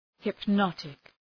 Προφορά
{hıp’nɒtık}